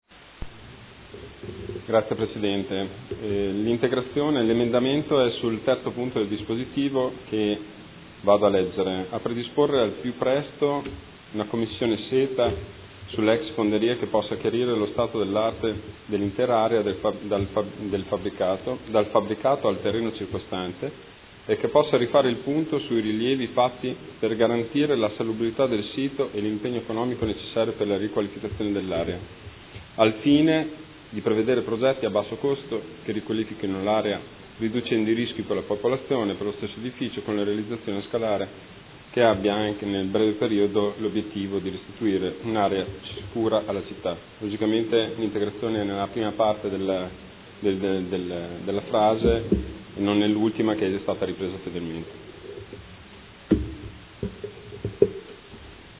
Marco Bortolotti — Sito Audio Consiglio Comunale